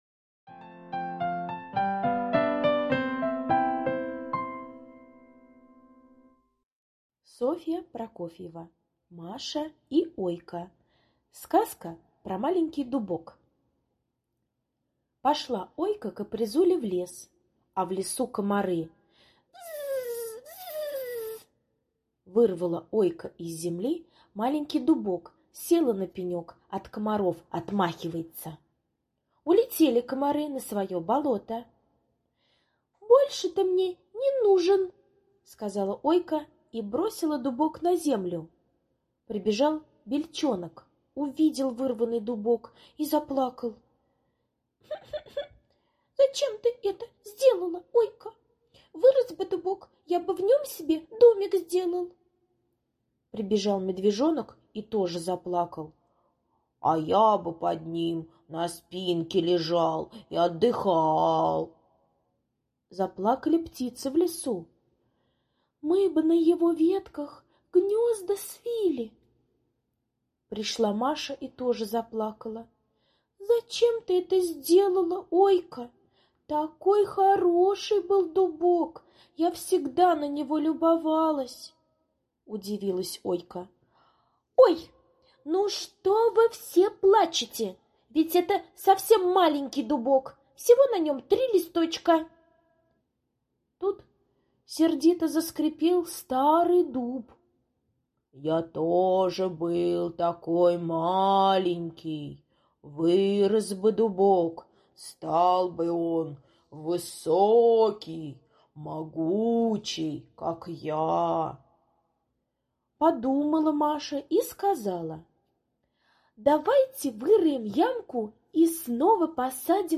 Сказка про маленький дубок - аудиосказка Прокофьевой С. Однажды Ойка в лесу вырвала маленький дубок чтобы отгонять комаров.